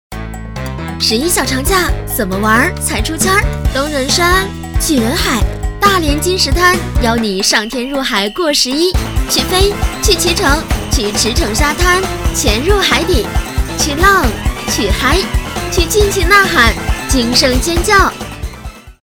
100%人工配，价格公道，配音业务欢迎联系：
B女80号